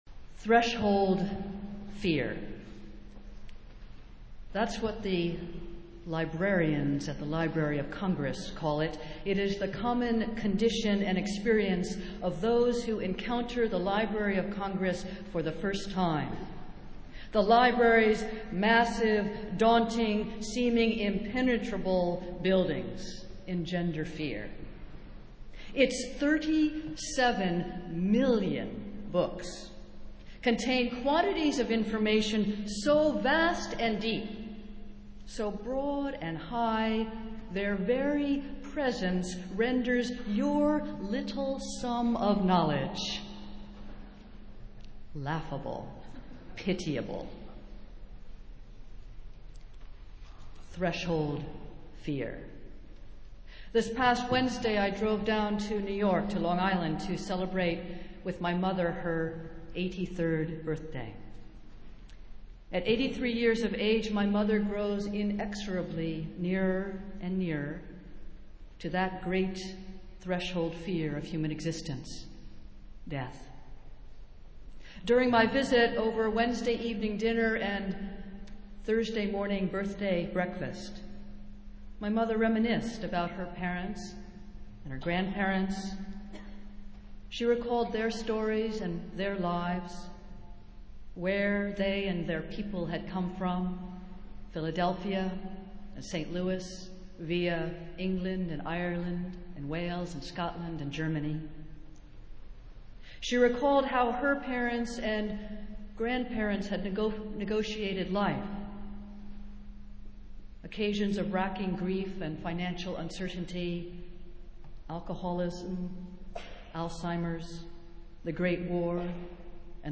Festival Worship - Seventeenth Sunday after Pentecost